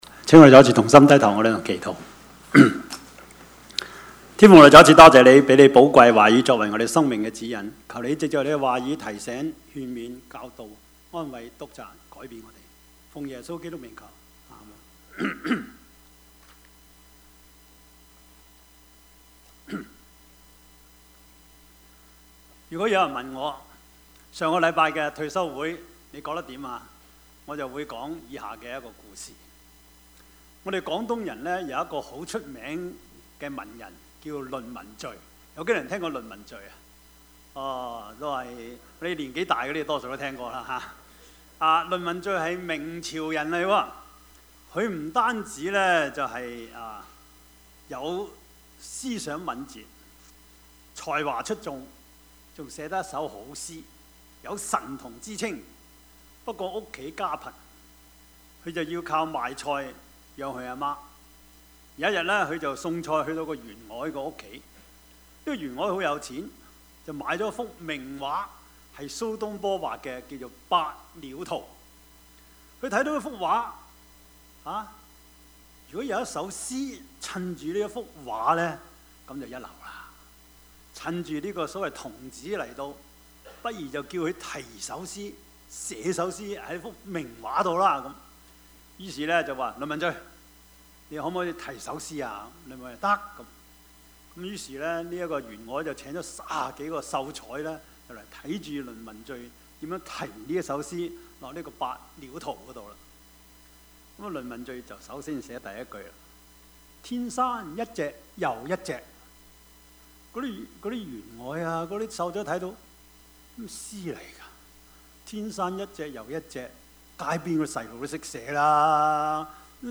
Service Type: 主日崇拜
Topics: 主日證道 « 耶穌也詫異 主的憐憫 »